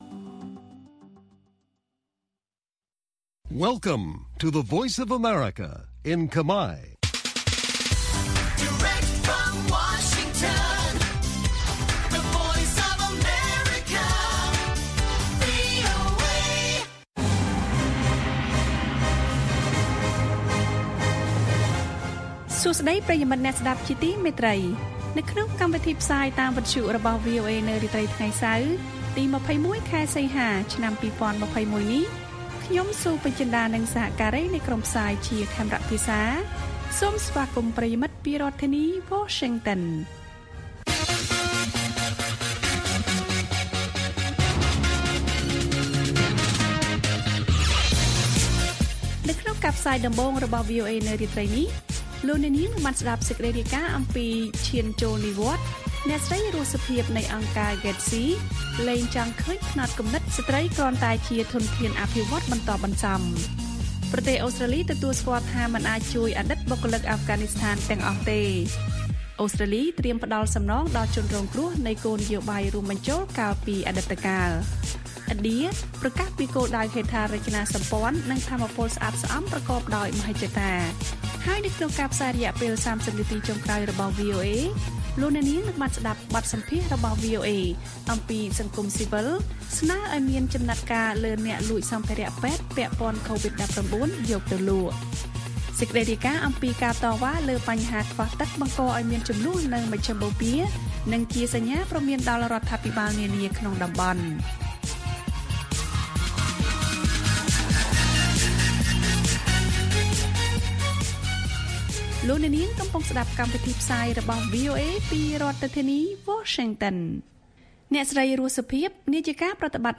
បទសម្ភាសន៍ Hello VOA៖ សង្គមស៊ីវិលស្នើឲ្យមានចំណាត់ការលើអ្នកលួចសម្ភារពេទ្យពាក់ព័ន្ធកូវីដ១៩យកទៅលក់។